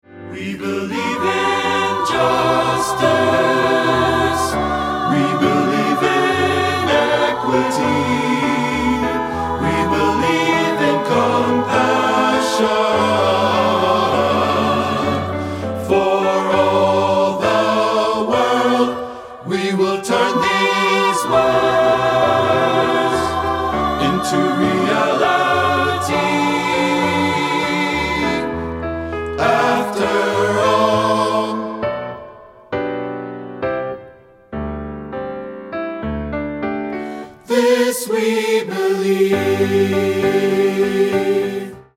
Hymn Composer
The song is structured as a call and response.
Accompaniment